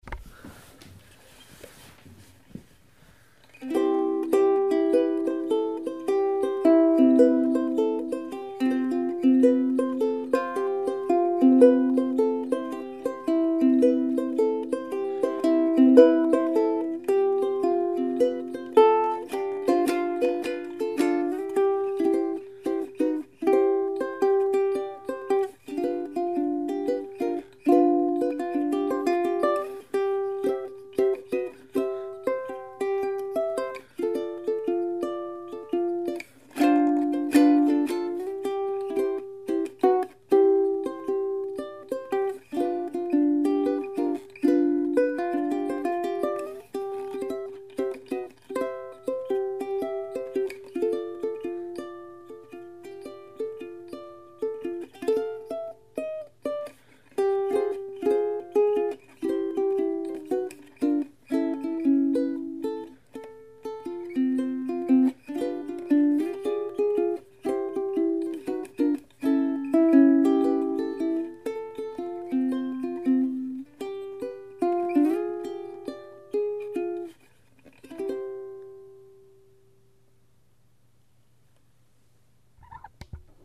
en ukelele